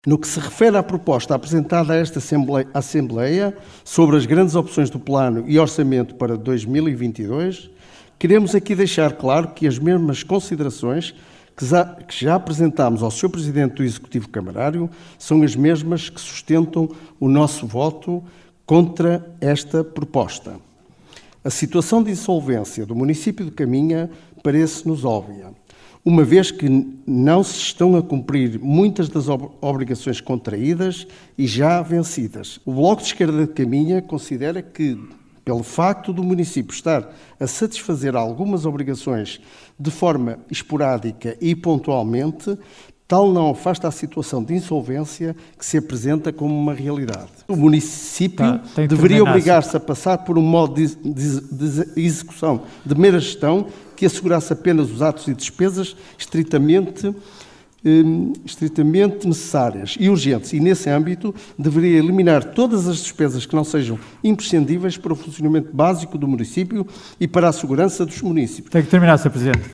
Realizada no cineteatro dos bombeiros voluntários de Vila Praia de Âncora, decorreu em tom de crispação de início ao fim.
A primeira intervenção coube ao deputado do Bloco de Esquerda Abílio Cerqueira que começou por sublinhar a situação de insolvência do município de Caminha que faz com que não consiga cumprir com as obrigações vencidas.